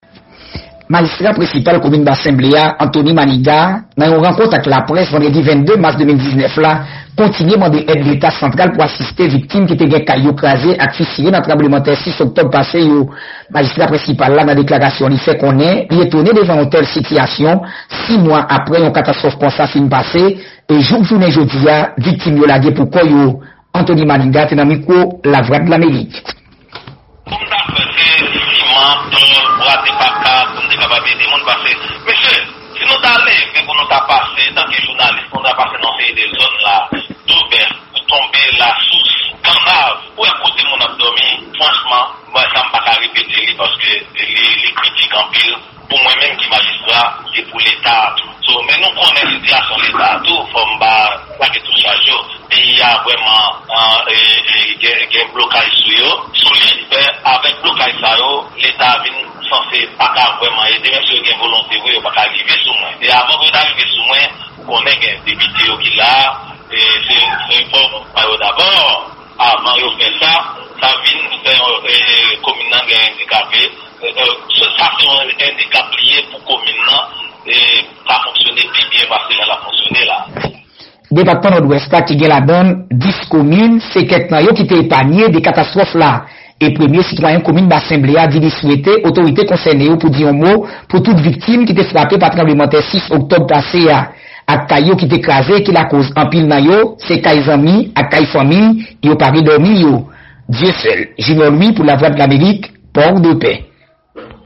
Magistra prinsipal komin Bassin Ble a, Anthony Maniguat, nan yon rankont ak laprès vandredi 22 mars 2019 kontinye mande èd leta santral pou asiste viktim kite gen kay yo kraze ak fisire nan tranblemanntè 6 oktob pase yo.
Yon repòtaj